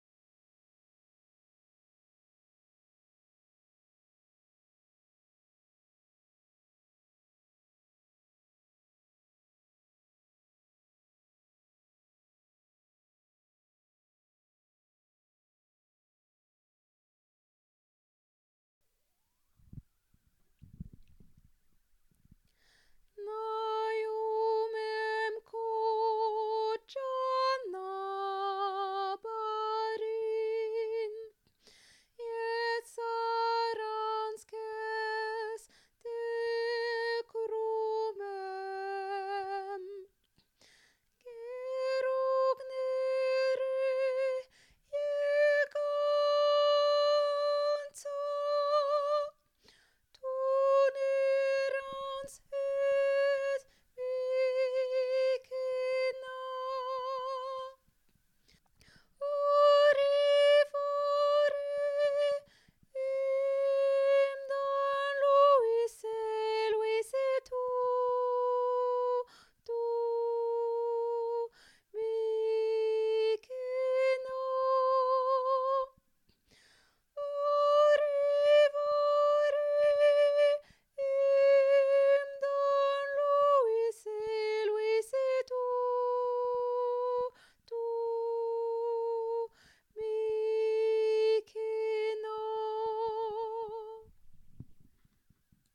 ob_c0cbc7_gueroug-ner-tenor.mp3